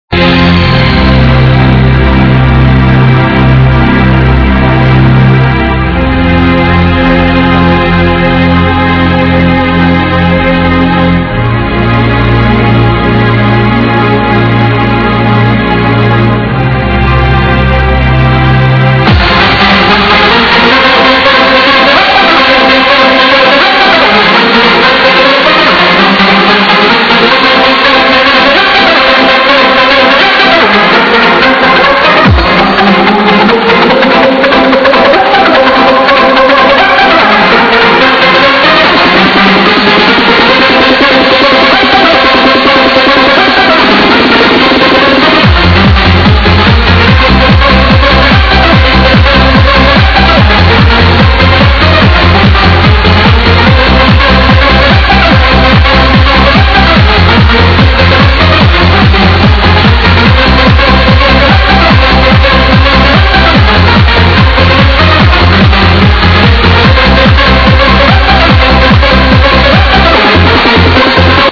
Exclamation Sweet-as Catchy Tune, Plz Help ID
This first one is taken from a trance/hard house set